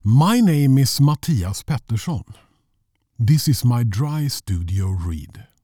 Male
Approachable, Authoritative, Confident, Conversational, Corporate, Deep, Energetic, Engaging, Versatile, Warm
European english with a slight swedish twang
Microphone: Austrian Audio OC18